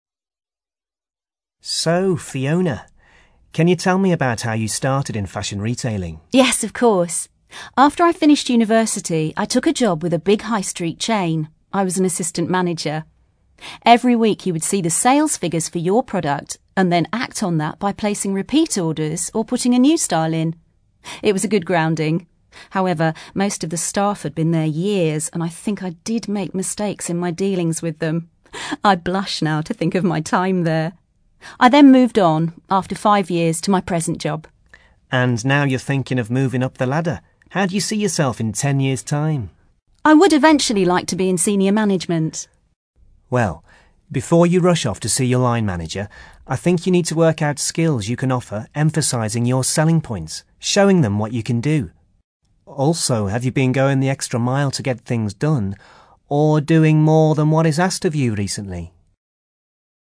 ACTIVITY 26: You are going to listen to part of an interview with a woman who works in retail management.